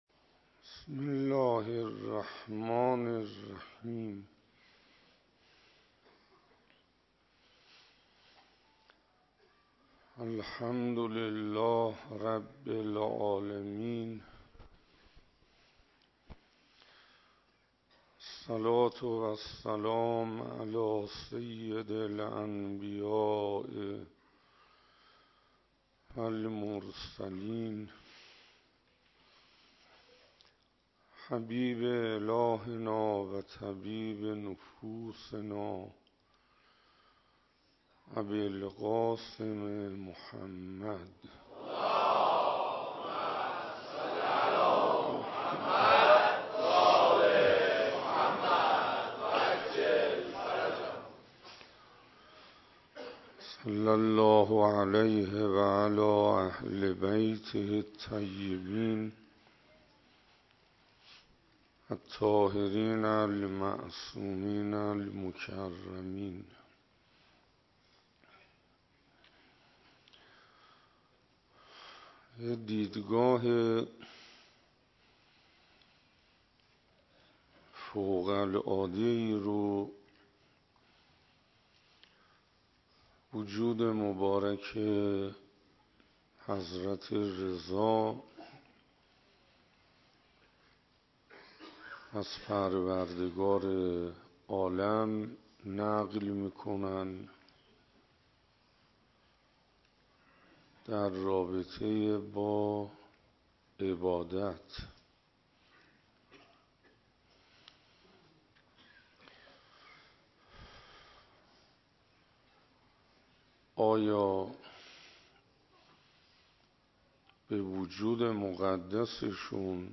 ویژه برنامه شهادت امام رضا(ع)مسجد هدایت انصاریان